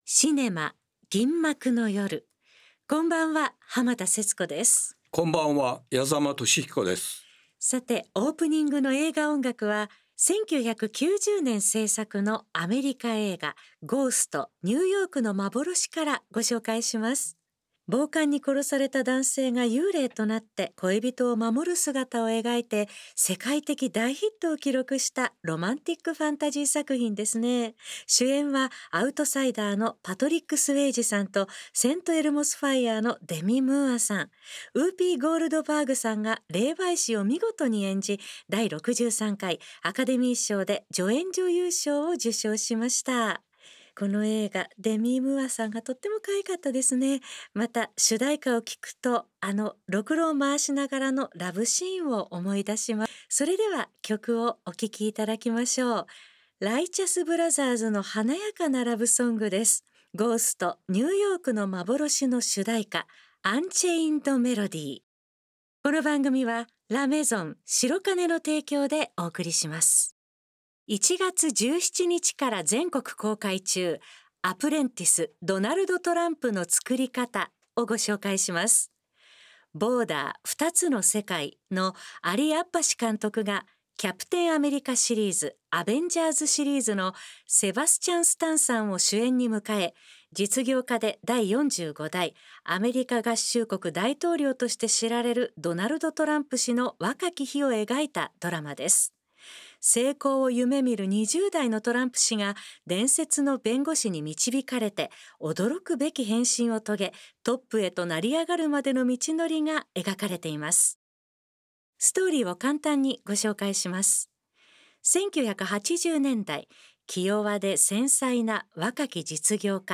最新の映画情報と過去の名作映画を音楽と共に紹介する30分。